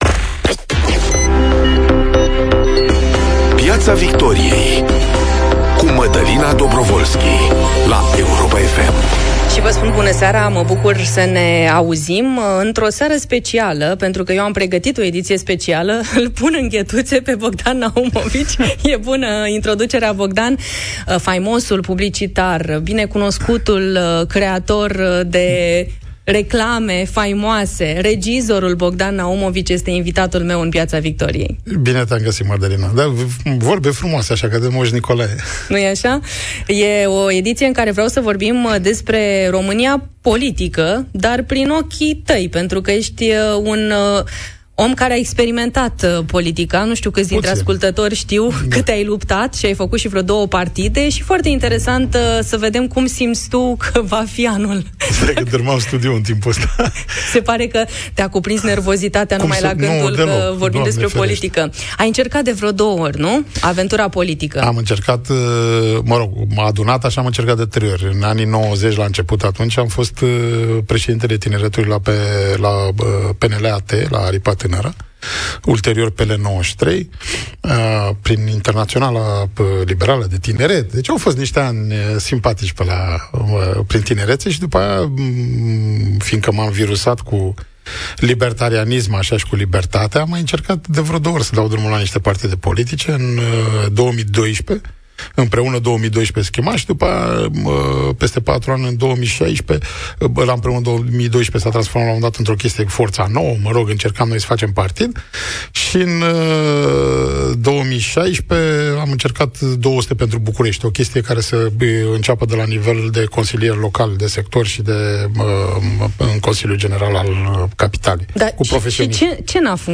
Ioana Ene Dogioiu vorbește, în Piața Victoriei, cu invitata sa Elena Lasconi, despre candidatura pentru PE, secretul fondurilor europene la Câmpulung sau despre impactul pachetului fiscal.